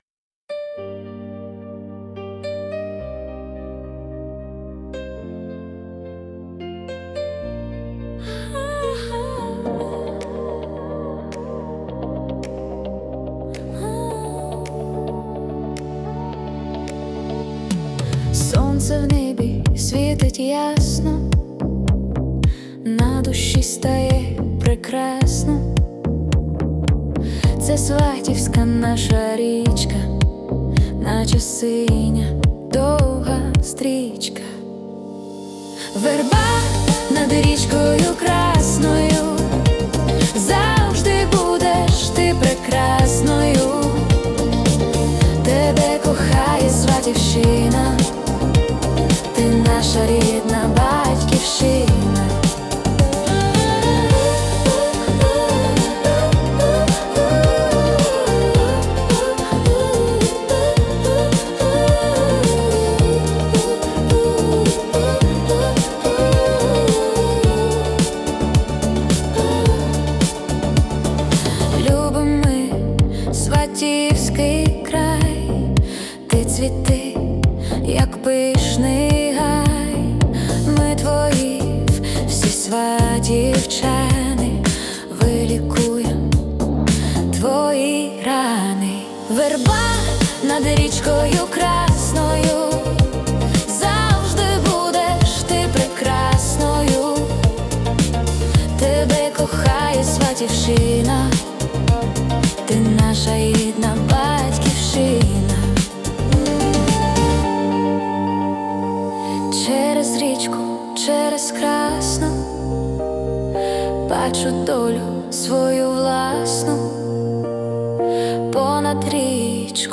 Synth-Pop / Lyrical